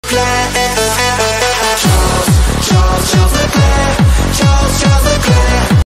charles charles charles leclerc Meme Sound Effect